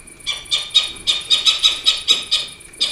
ATHENE NOCTUA - LITTLE OWL - CIVETTA